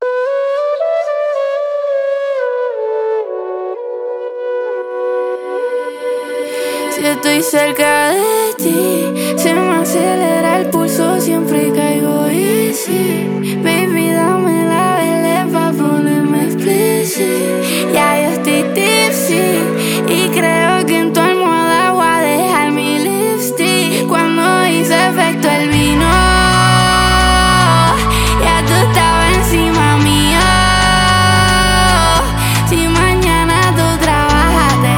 Скачать припев
Latin